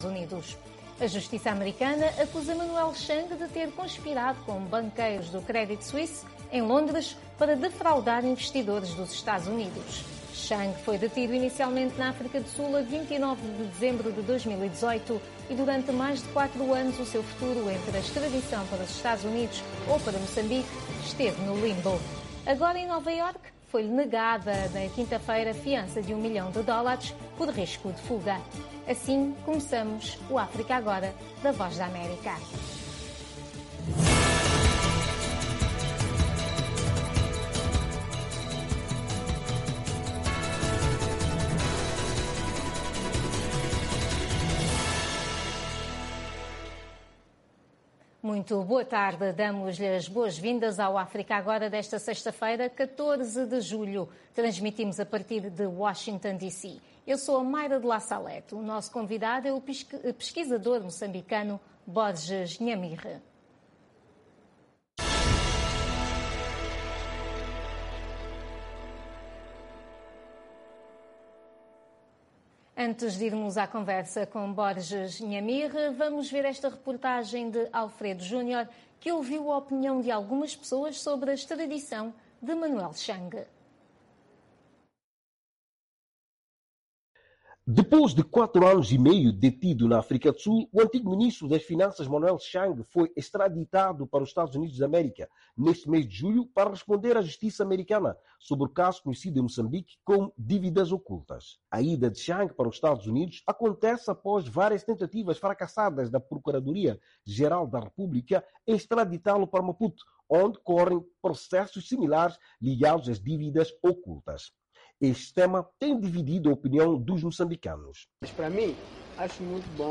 África Agora, o espaço que dá voz às suas preocupaçōes. Especialistas convidados da VOA irão comentar... com a moderação da Voz da América. Um debate sobre temas actuais da África Lusófona.